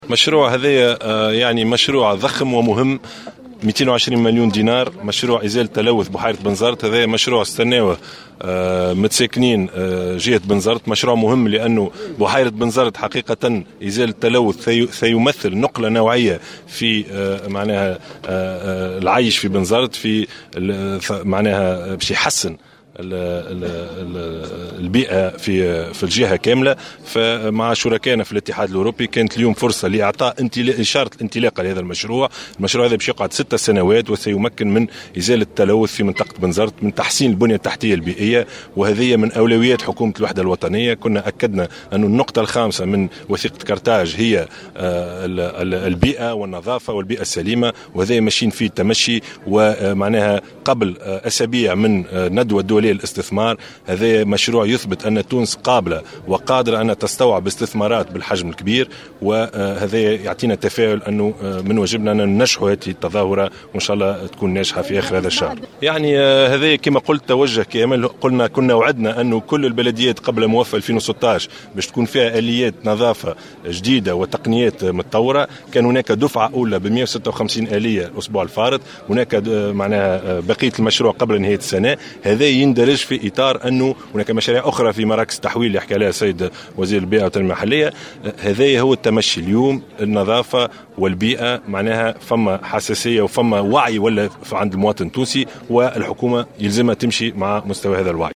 وبين الشاهد في تصريح لمراسل الجوهرة أف أم في الجهة، أن هذا المشروع الضخم الذي لطالما انتظره الأهالي في جهة بنزرت، بلغت كلفته الجملية 200 مليون دينار وتمتد فترة إنجازه على 6 سنوات، معتبرا أن إزالة التلوث من الجهة سيمثل "نقلة نوعية" في جودة العيش في بنزرت.